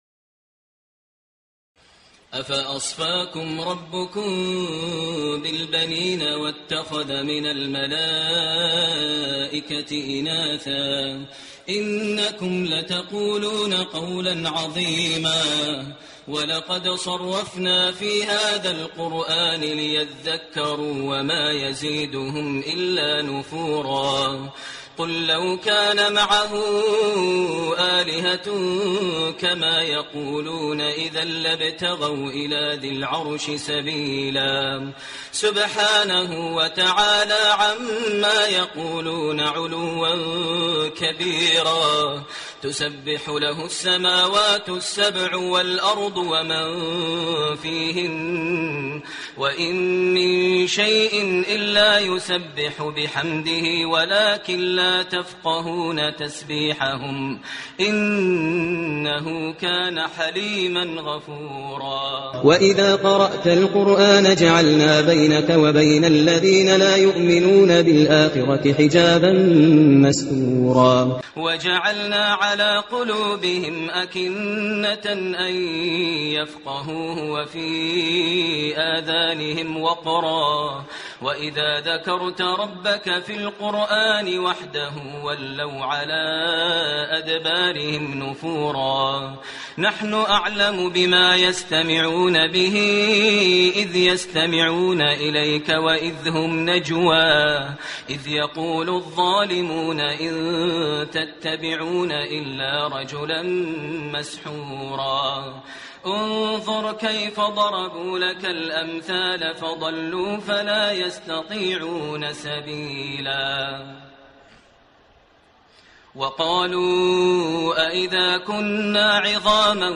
تراويح الليلة الخامسة عشر رمضان 1429هـ من سورة الإسراء (40-111) Taraweeh 15 st night Ramadan 1429H from Surah Al-Israa > تراويح الحرم المكي عام 1429 🕋 > التراويح - تلاوات الحرمين